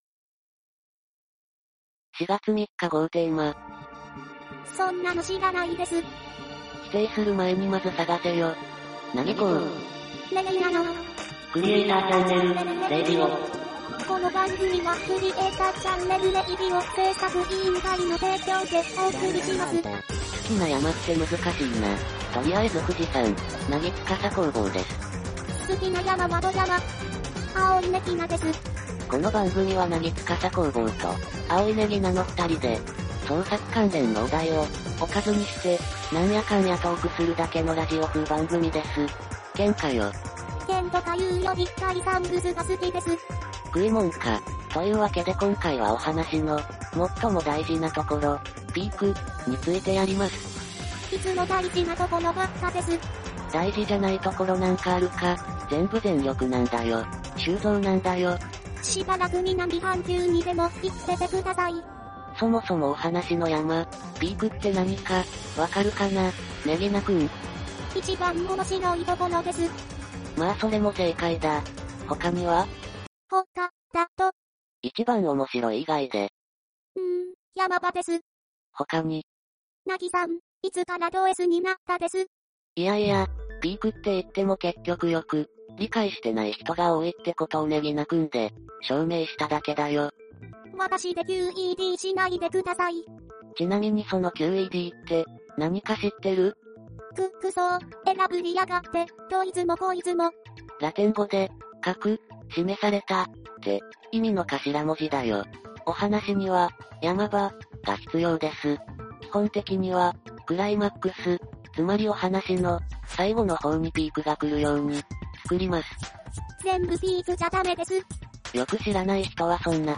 諸事情で現在はちまちまと週一の個人製作ラジオ番組風動画を配信したりしております。